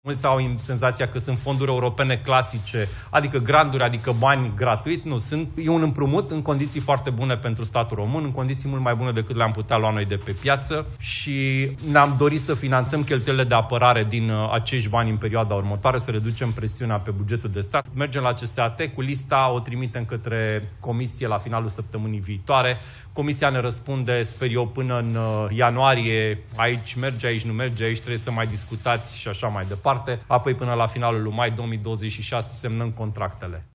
Programul european SAFE – pe apărare și infrastructură – aduce României 16 miliarde de euro cu dobândă de 3% și rambursare în 40 de ani. Declarația a fost făcută de ministrul Apărării, Ionuț Moșteanu, după ședința de Guvern.